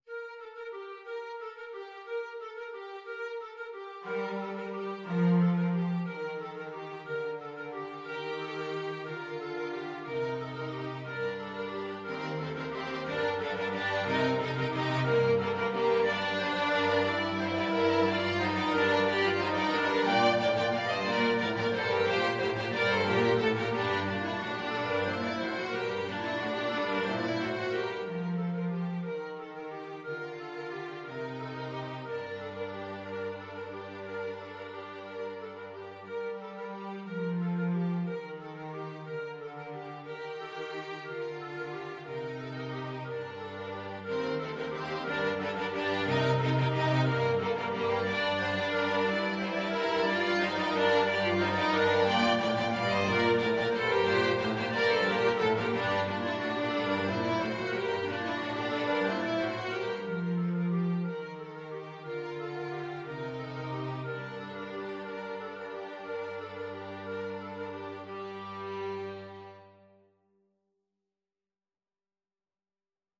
ClarinetFluteOboeAlto Saxophone
ViolinTrumpetFrench Horn
CelloTrumpetAlto SaxophoneFrench Horn
3/4 (View more 3/4 Music)